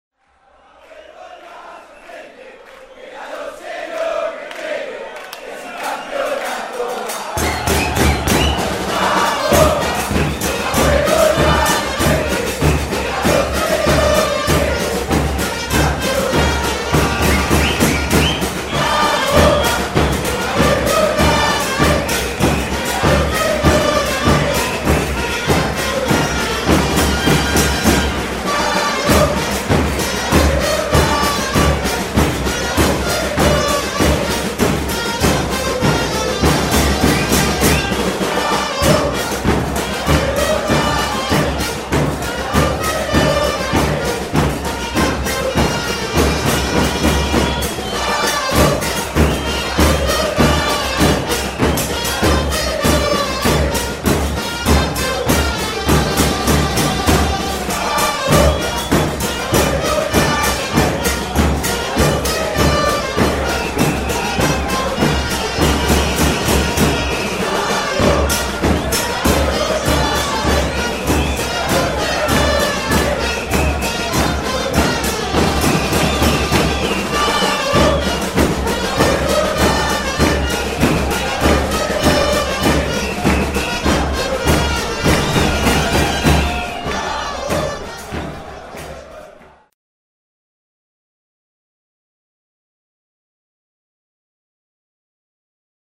Zde si můžete stáhnout 4 chorály Bocy (3 z nich natočeny přímo v kotli La Doce u kapely):
Chorál 3 (1,46 MB)